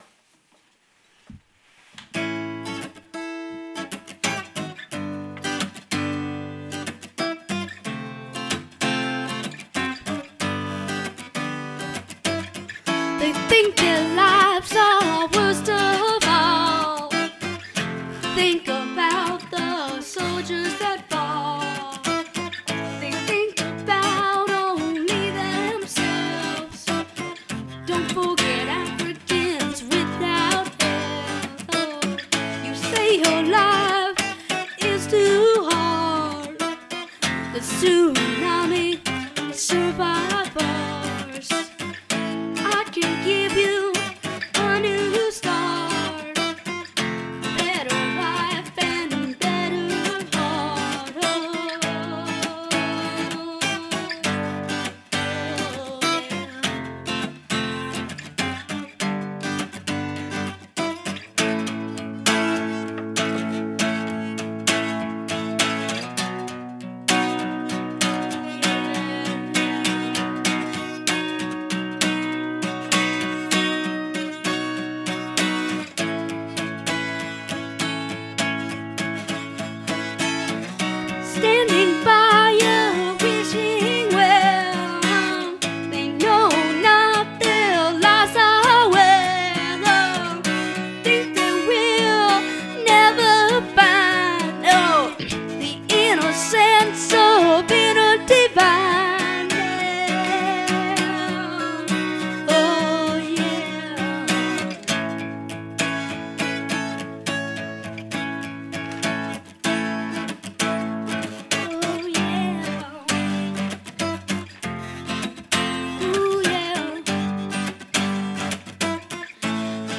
composer and guitarist